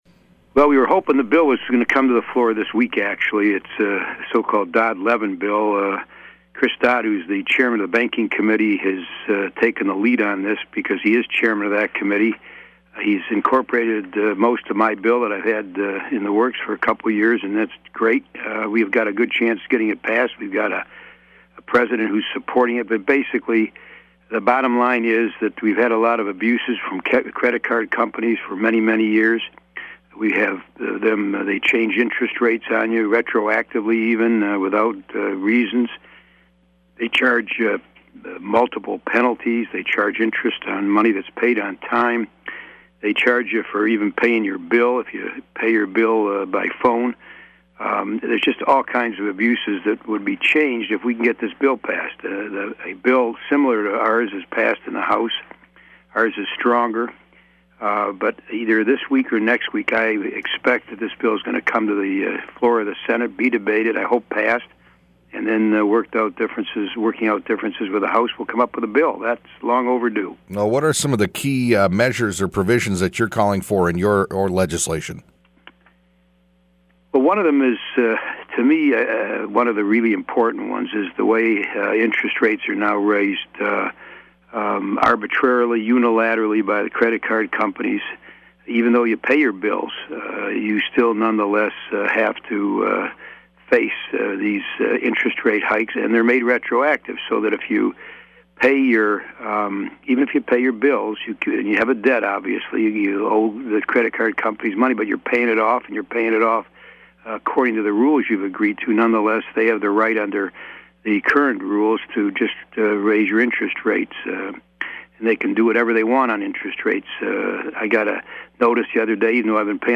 Old Interviews Archive